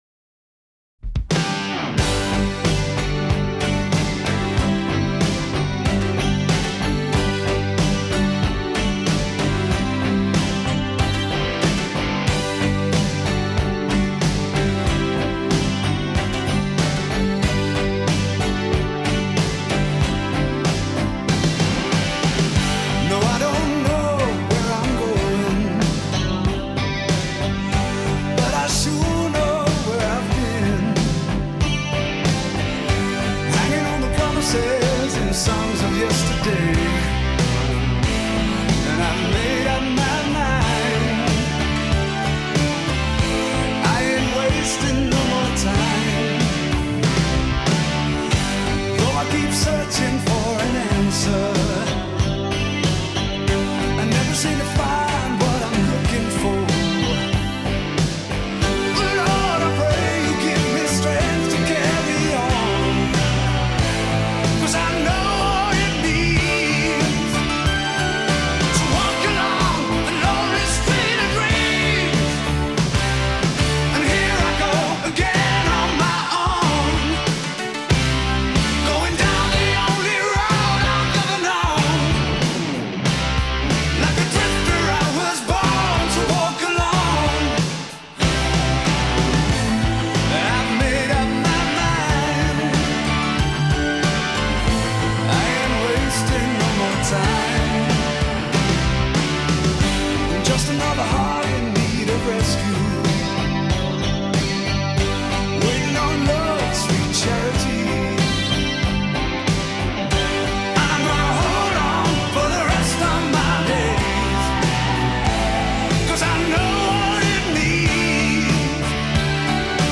Жанр: Хард-рок